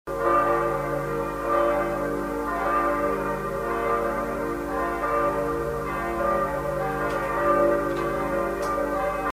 Die drei Glocken der Auferstehungskirche wurden im März 1942 zu Kriegszwecken ausgebaut, abtransportiert und eingeschmolzen; ab 1951 nach und nach in ihren ursprünglichen Tonlagen (g´, f´, d´) ersetzt und am 2. Advent des Jahres 2005 um eine vierte Glocke (b´) erweitert.
Auferstehungskirche_Oldenburg_Gelaeut.mp3